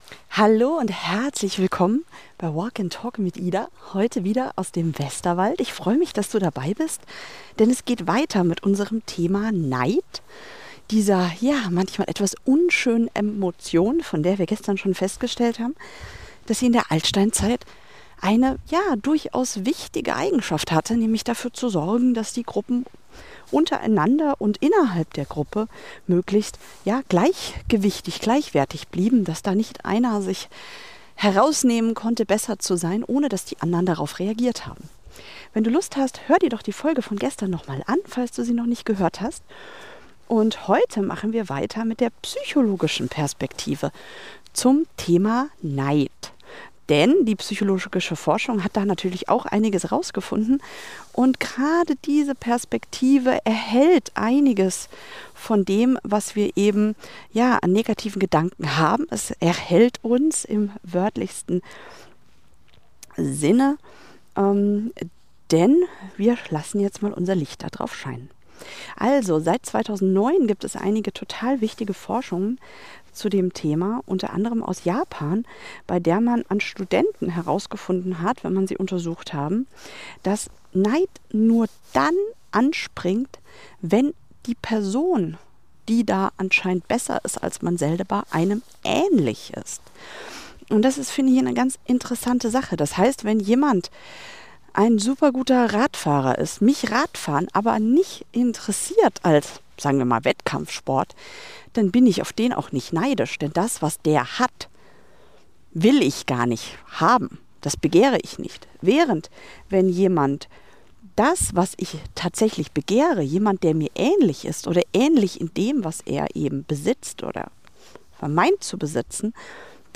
Keine trockene Wissenschaft, sondern verständlich erklärt – beim Spaziergang durch den Westerwald, vorbei an wilden Wildschweinspuren.